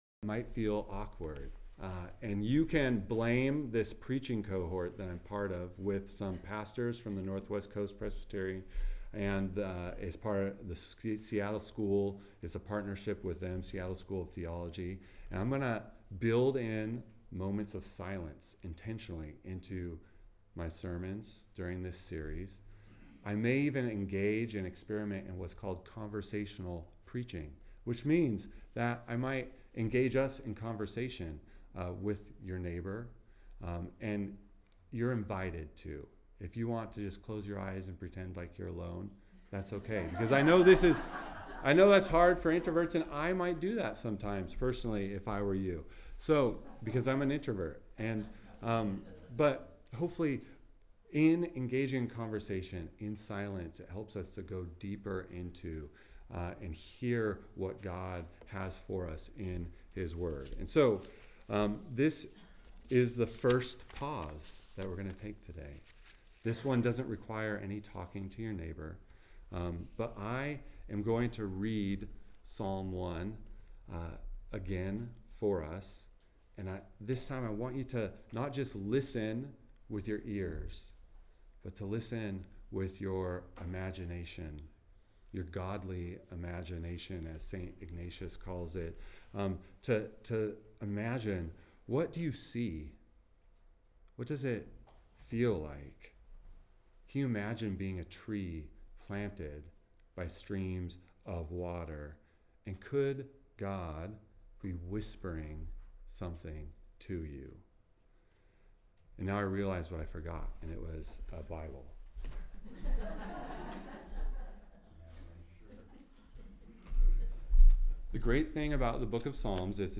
sermon+6-29+Edit.mp3